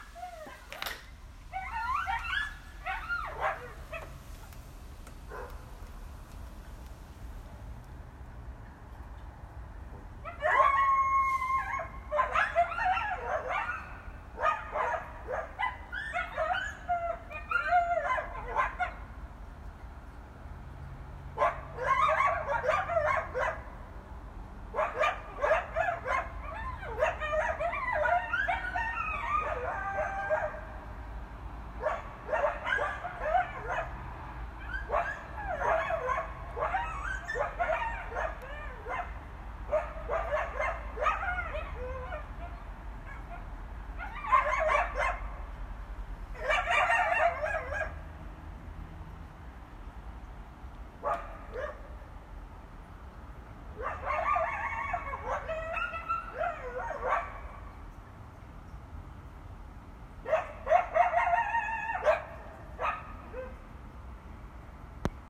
Coyote Voicings: Howls, Yips, Barks, & More | Coyote Yipps
26)a And here is an audio of social communication one evening: it’s two coyotes during their greeting session before heading off for the evening trekking: it sounds super-conversational, doesn’t it?